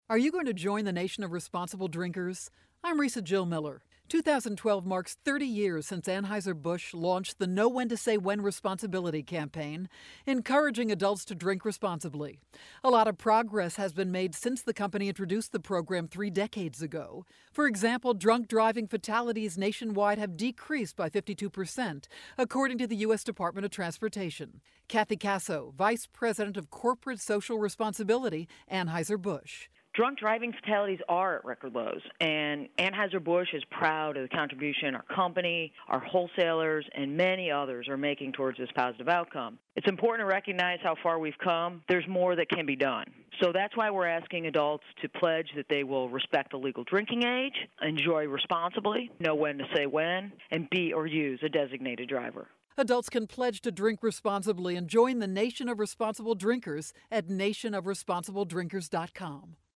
July 5, 2012Posted in: Audio News Release